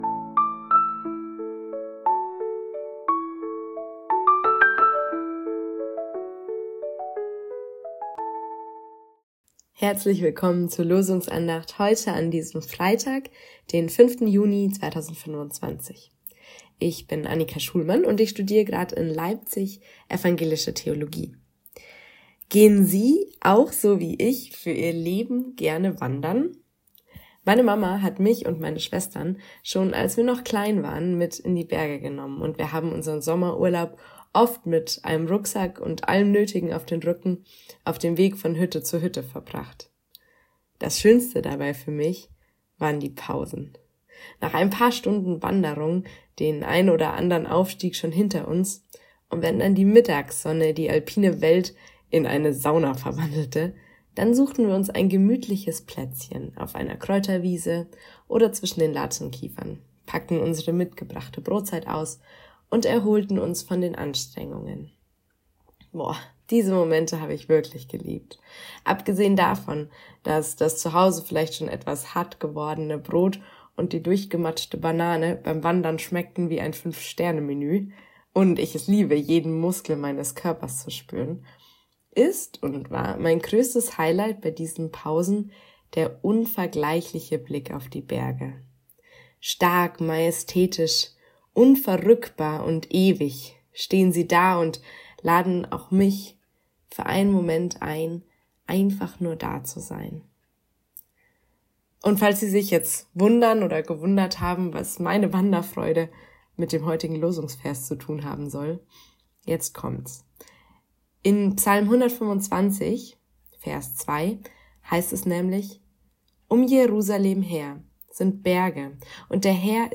Losungsandacht für Freitag, 06.06.2025